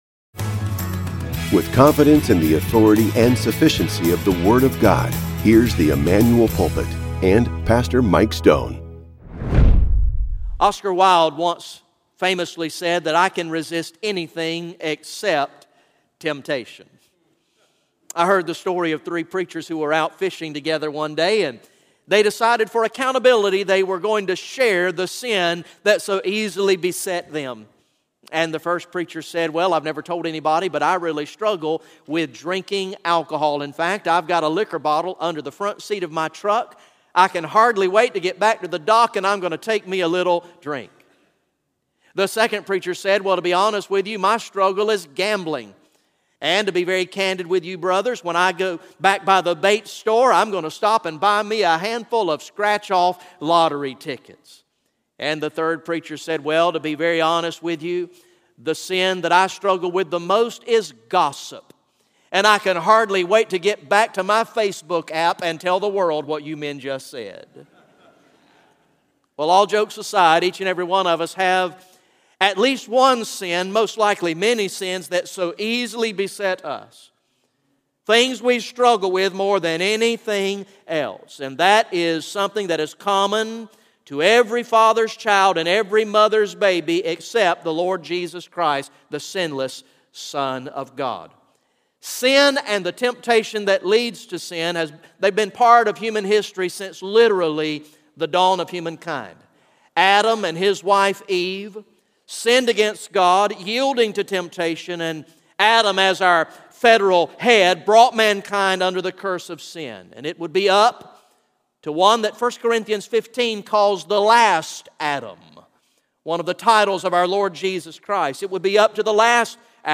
GA Message #10 from the sermon series entitled “King of Kings